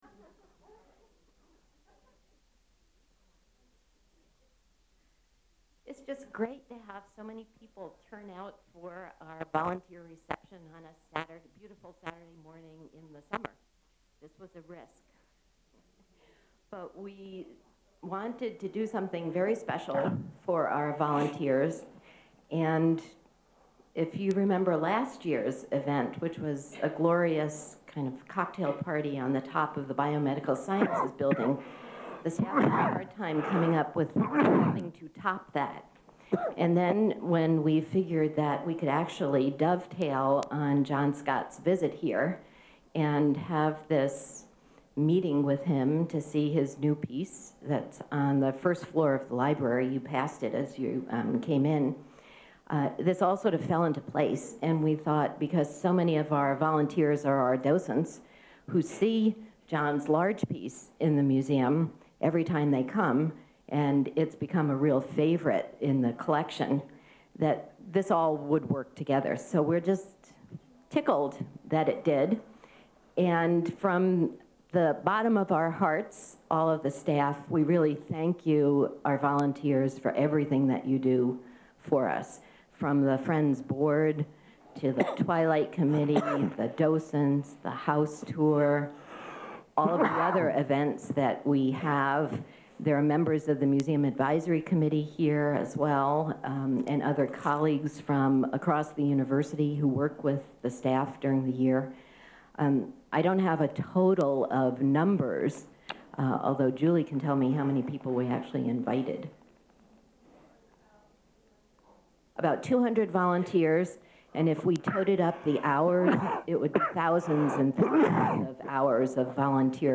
Sculptor John Tarrell Scott gives a lecture at Michigan State University
Renowned sculptor and Michigan State University alumnus John T. Scott, talks about his life and work during a lecture at MSU.
Recorded by Michigan State University Kresge Art Center, June 19, 2004.